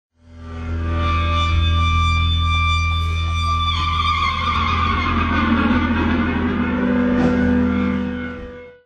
neigh.wma